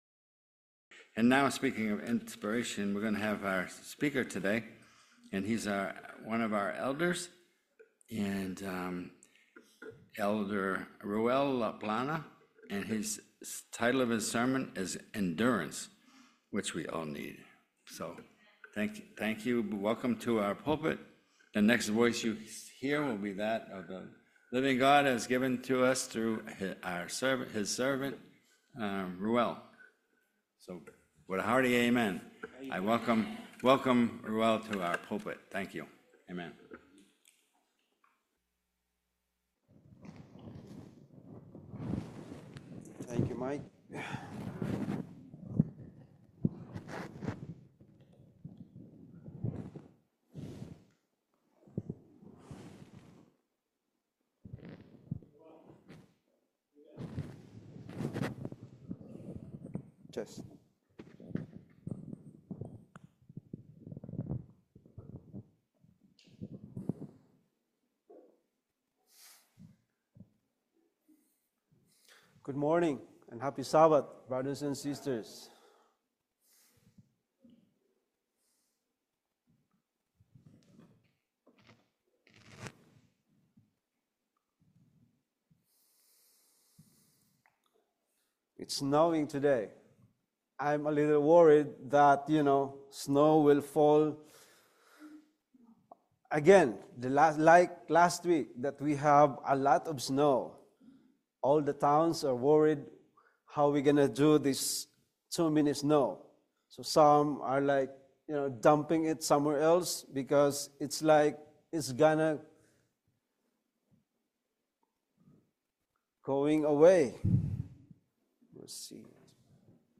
Services for February 2026